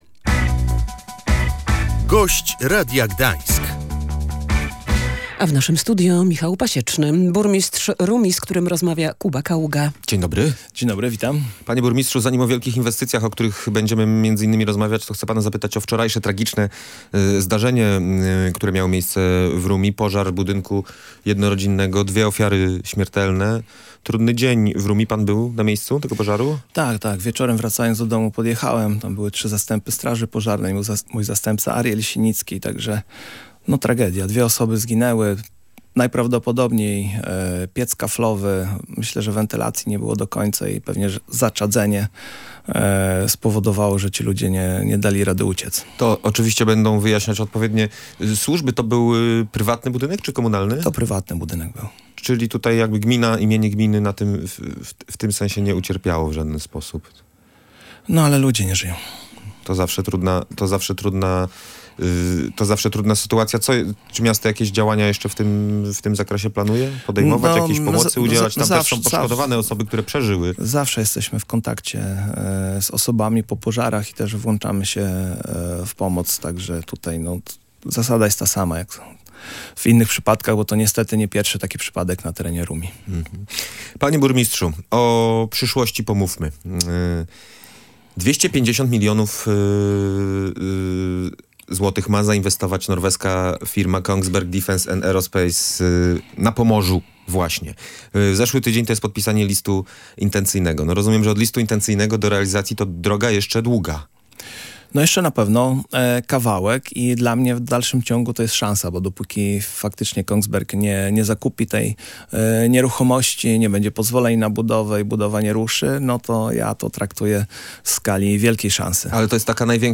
Fabryka broni, która powstanie w Rumi, to szansa na rozwój miasta, ale także poprawę bezpieczeństwa – mówił w Radiu Gdańsk burmistrz Michał Pasieczny.